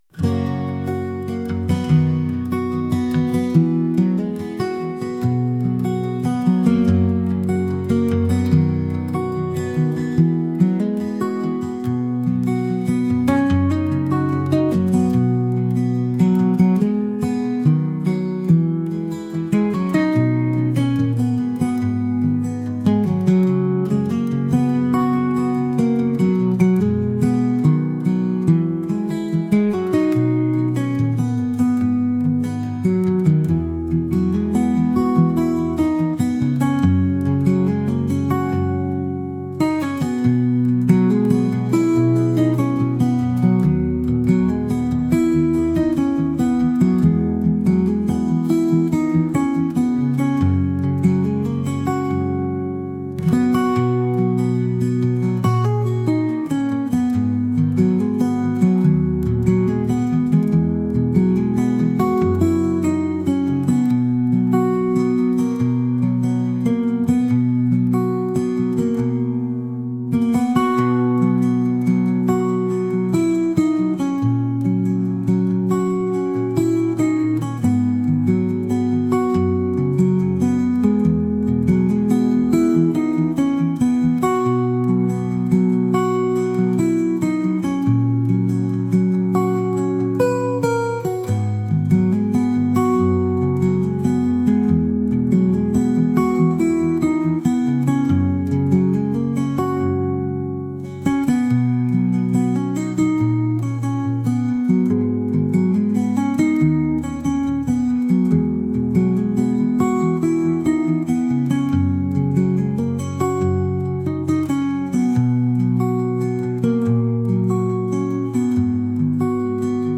ギターを主体とした穏やかなカントリー曲です。